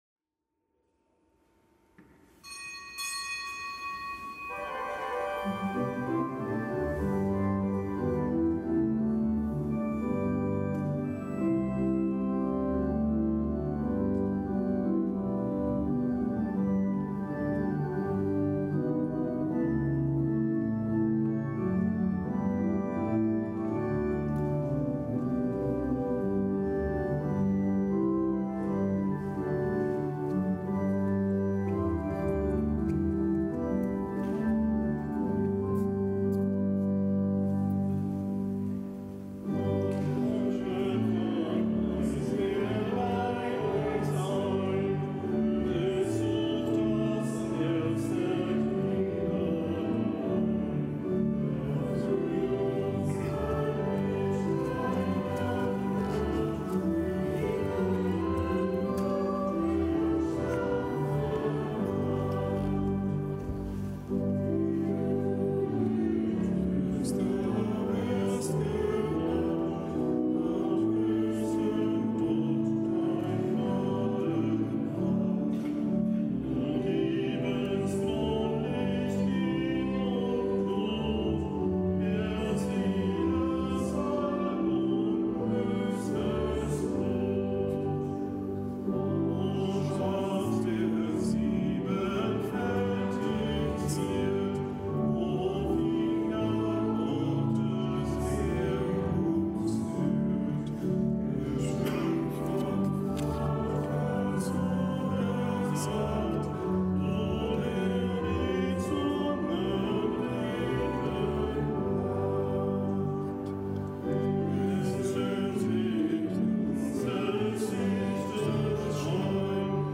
Kapitelsmesse aus dem Kölner Dom am Mittwoch der dreißigsten Woche im Jahreskreis. Zelebrant: Weihbischof Ansgar Puff.